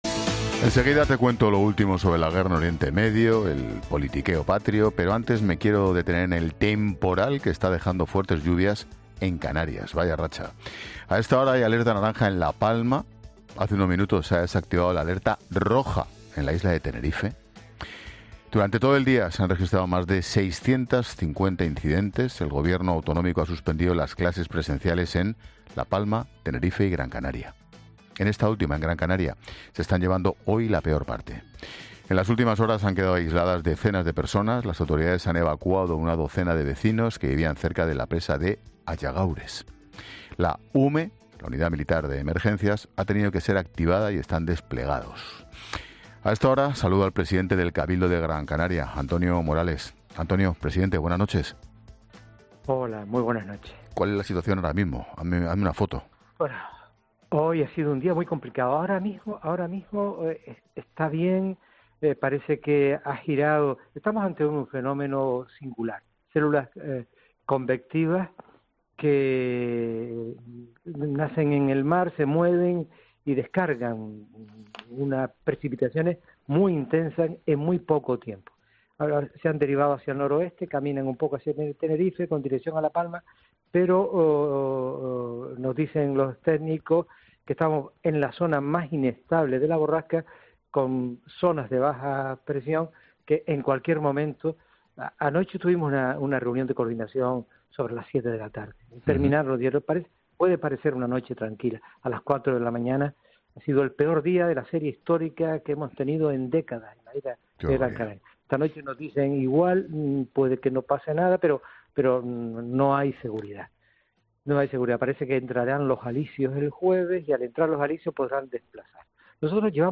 Expósito entrevista a Antonio Morales, presidente del cabildo de Gran Canaria
Así lo ha explicado el presidente del cabildo de Gran Canaria, Antonio Morales, en el programa 'La Linterna' de COPE.